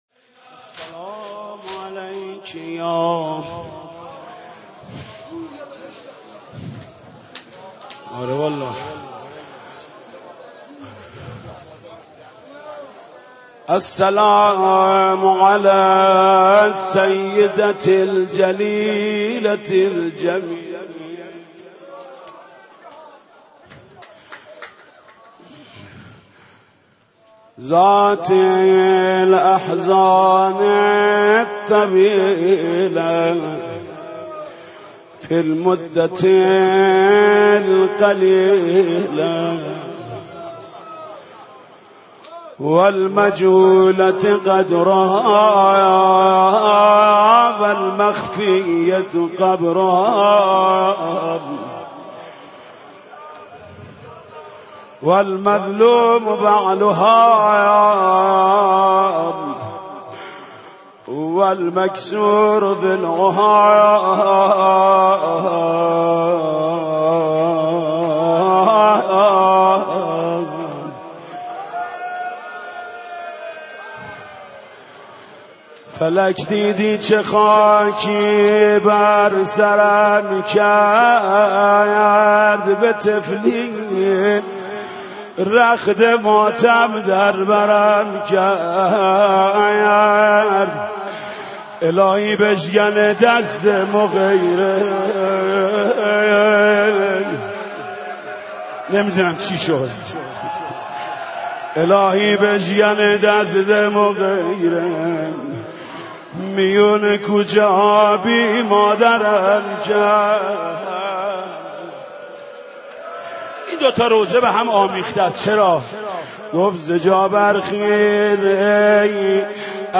roze-hazrat-zahra.mp3